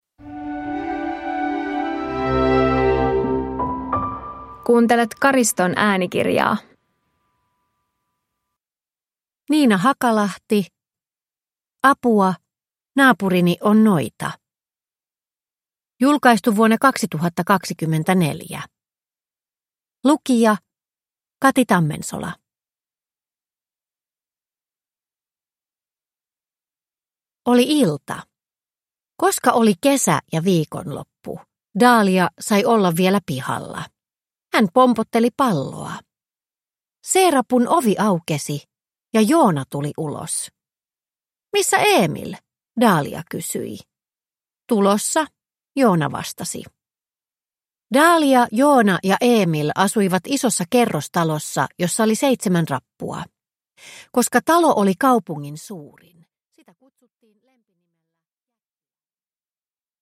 Apua, naapurini on noita! – Ljudbok